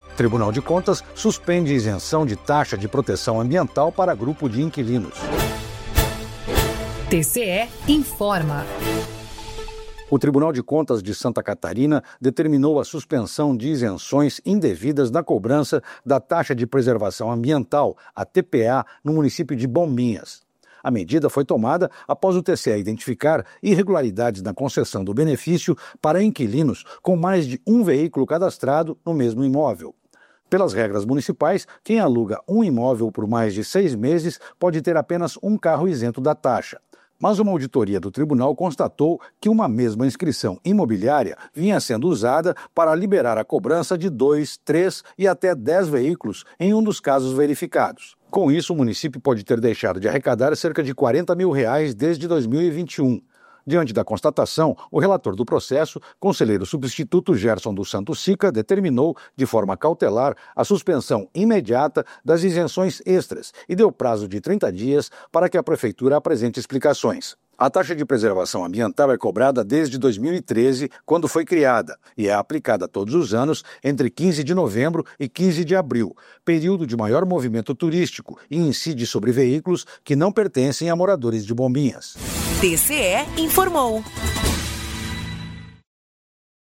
VINHETA TCE INFORMOU